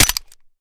weap_mike_fire_plr_mech_last_03.ogg